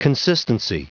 Prononciation du mot consistency en anglais (fichier audio)
Prononciation du mot : consistency